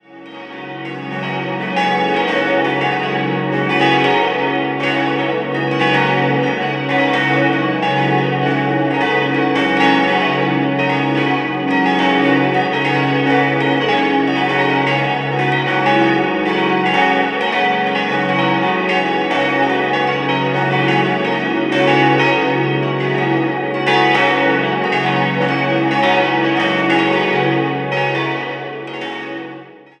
Anfang der 1960er-Jahre kam es zu einer großzügigen Erweiterung der Kirche, dabei ersetzt man auch den Fassadenturm durch einen modernen Neubau. 6-stimmiges Geläut: cis'-fis'-gis'-ais'-cis''-dis'' Alle Glocken wurden im Jahr 1961 von Friedrich Wilhelm Schilling in Heidelberg gegossen.